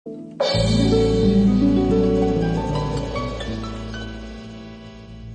Vintage Jazz Latin Other Other